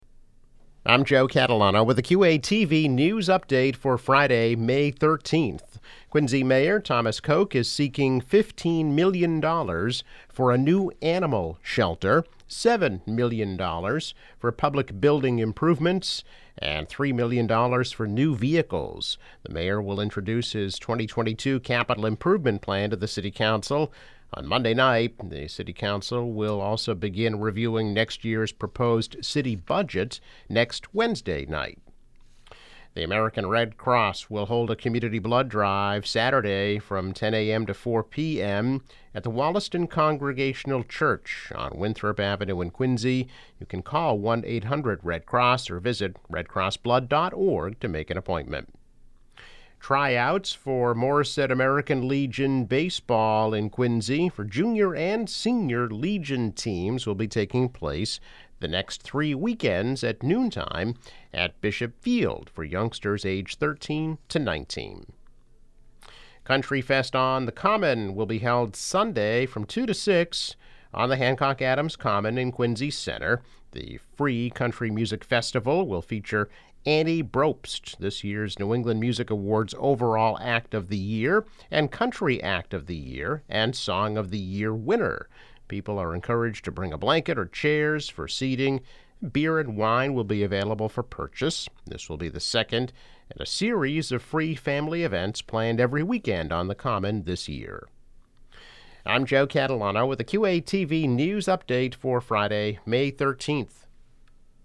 News Update - May 13, 2022